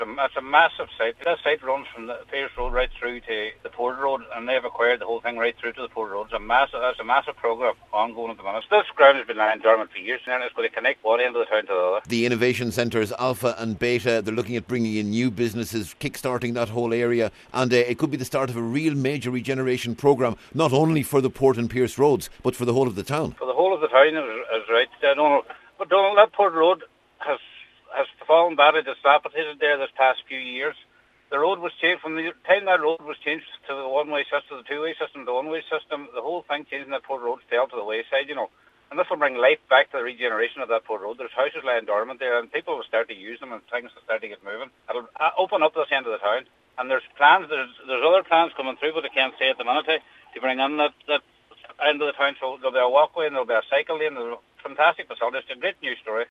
Mayor Cllr Kevin Bradley says this is an important milestone…………….